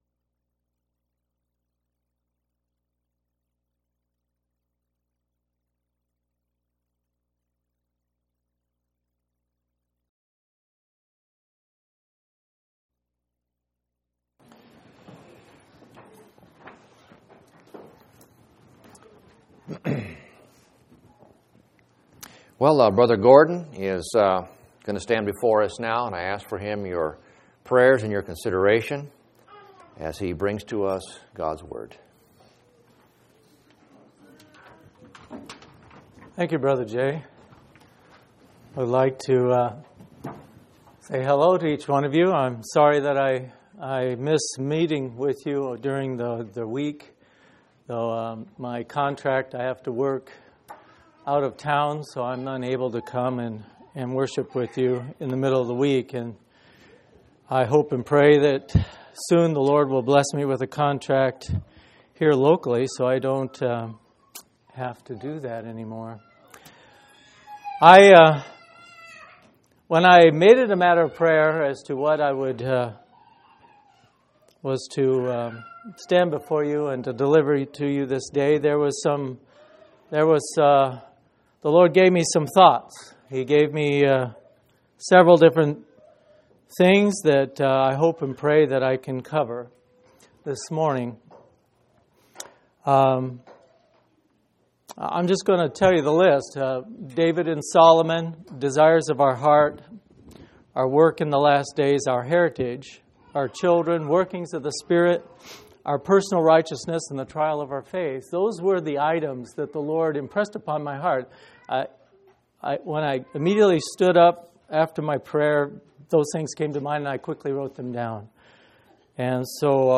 10/10/2004 Location: Phoenix Local Event